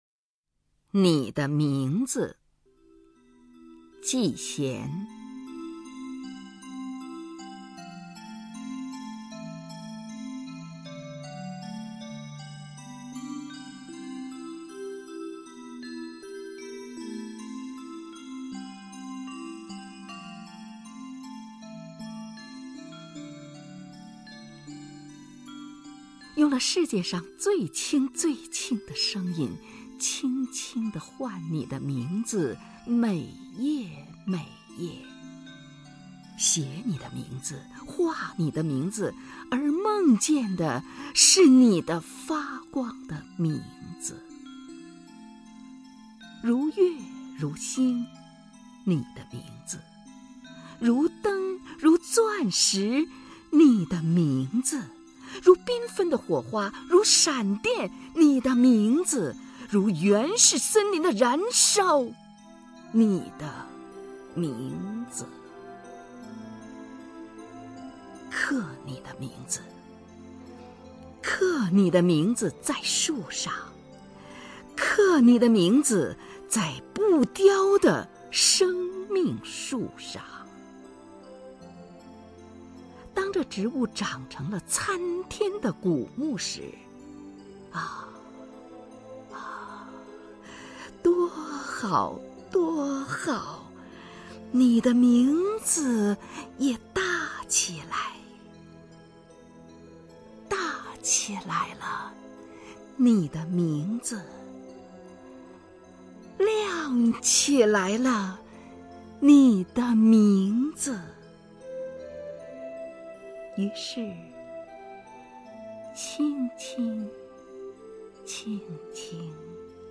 首页 视听 名家朗诵欣赏 虹云
虹云朗诵：《你的名字》(纪弦)